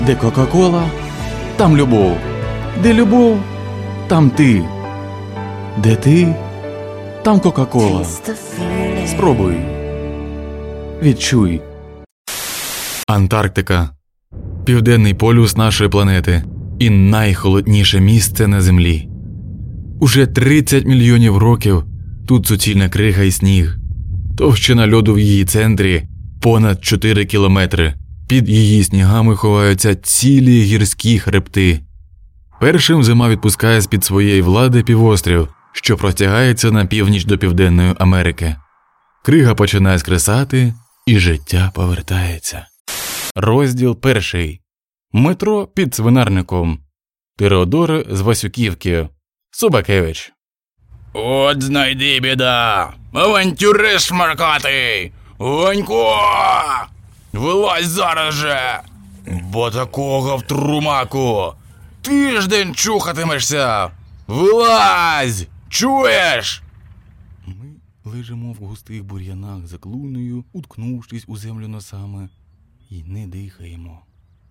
Чоловіча
Баритон Бас